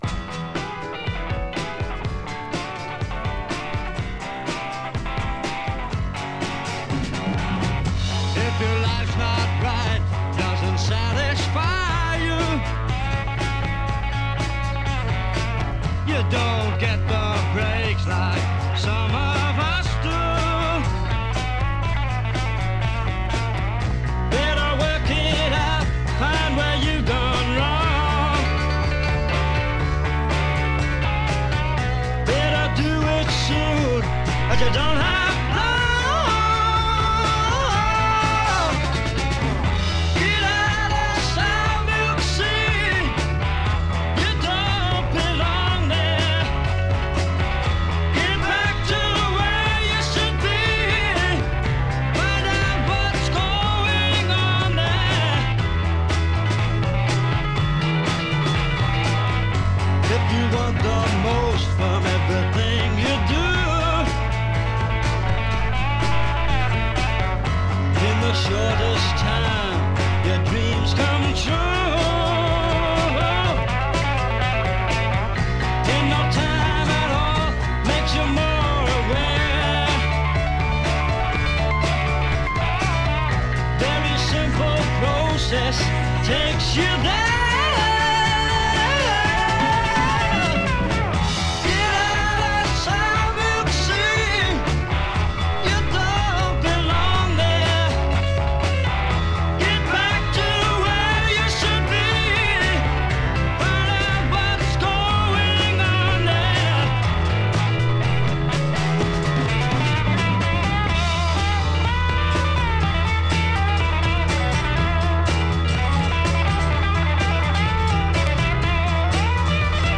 Recorded 26 June 1968 at EMI Recording Studios, London.
Intro 0:00 guitar, drums built on verse backing
coda 2:27 improv over harmonic pedal b'